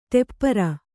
♪ ṭeppara